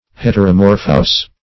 Heteromorphous \Het`er*o*mor"phous\